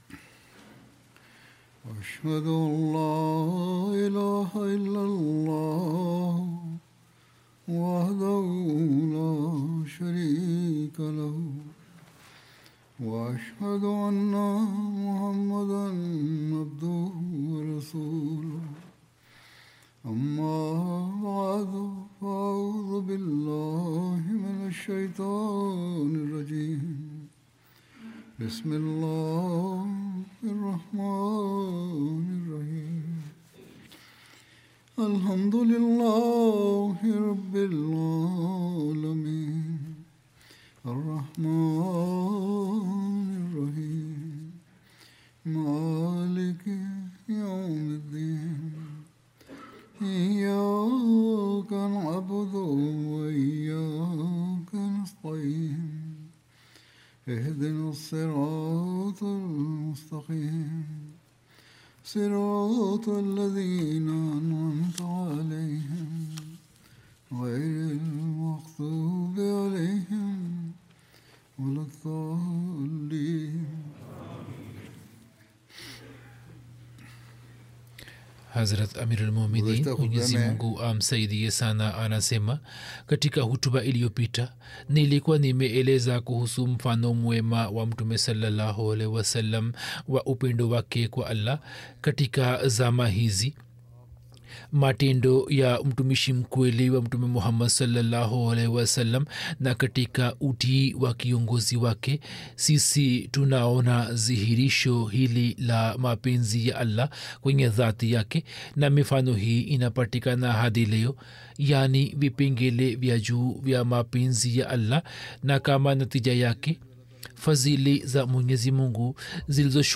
Swahili Friday Sermon by Head of Ahmadiyya Muslim Community